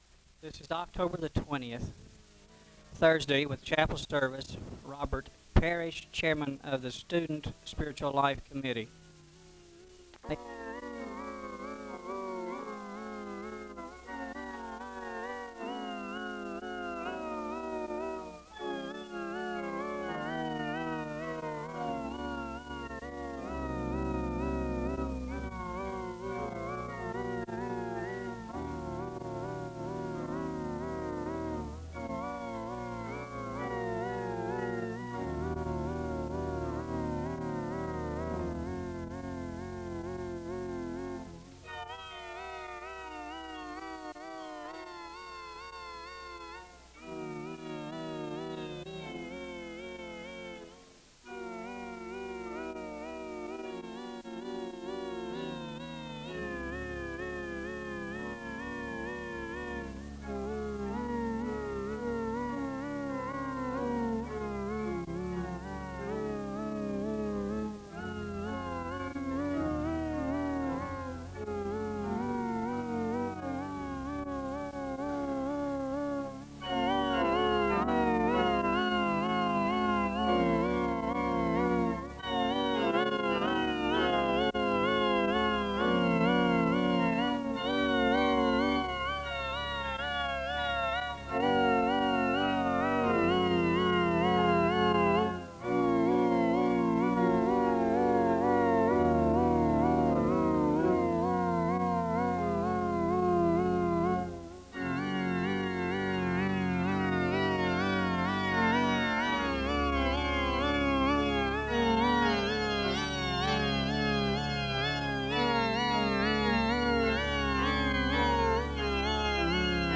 A hymn is played (0:10-4:29). Psalm 100 is read, followed by a word of prayer (4:30-5:22).
Another hymn is played (cut) (5:23-5:30).
The benediction is given (17:54-18:29).
Location Wake Forest (N.C.)